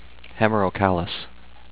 hem-mer-oh-cal-iss